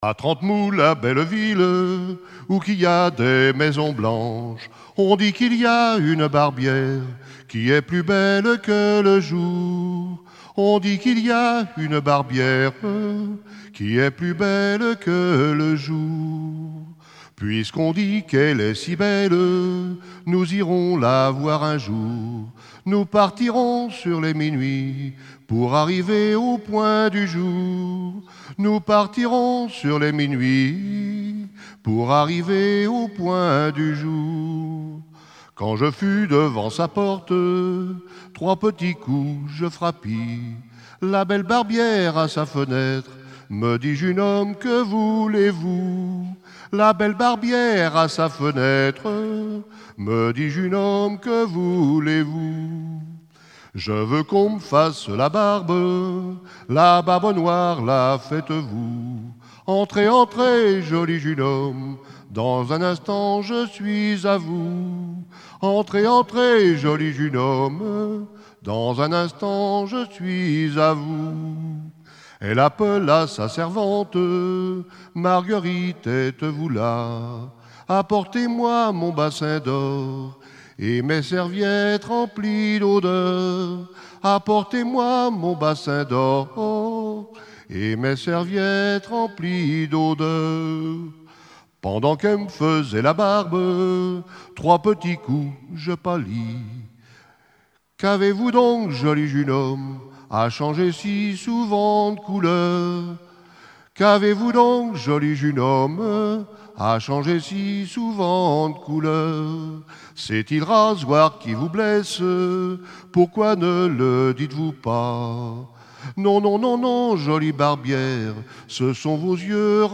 Genre laisse
Festival de la chanson traditionnelle - chanteurs des cantons de Vendée
Pièce musicale inédite